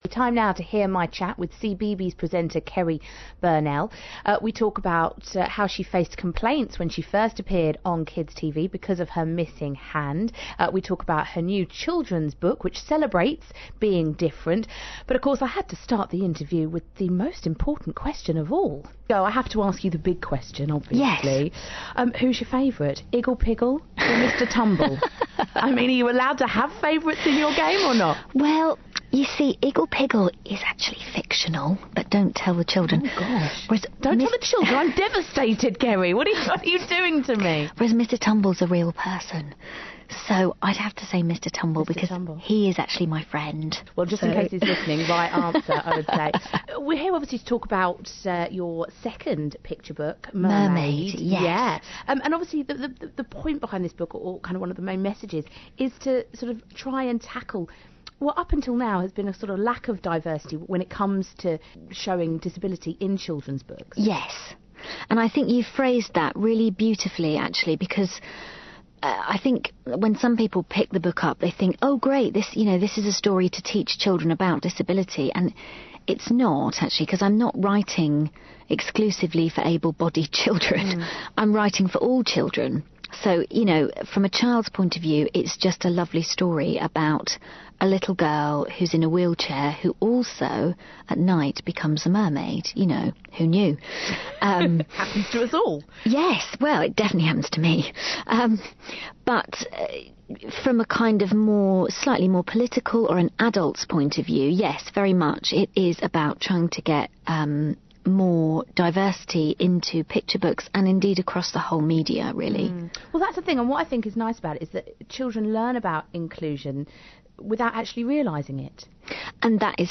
cBeebies presenter Cerrie Burnell